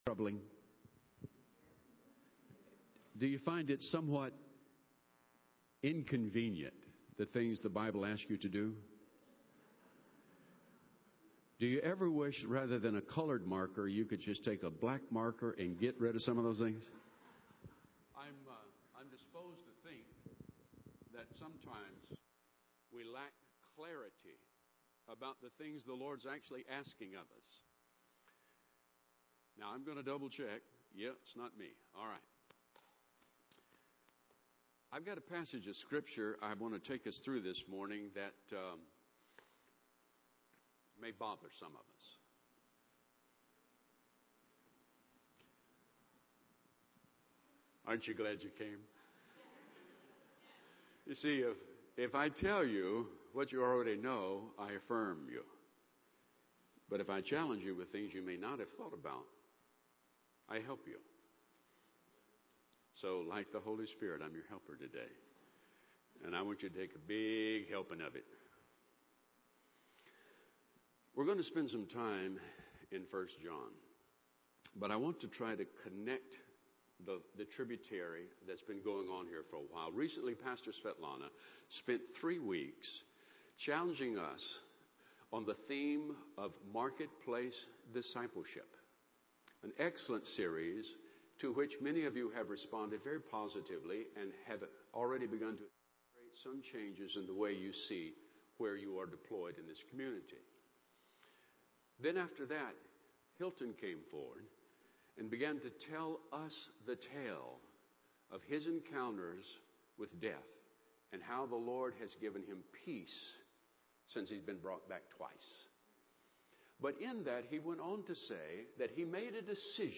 In this sermon from 4/13/2014